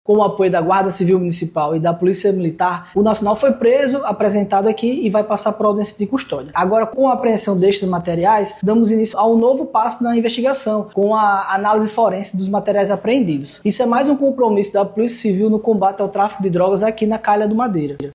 Os objetos apreendidos vão passar por perícia, em uma nova fase da investigação, destaca ainda o delegado.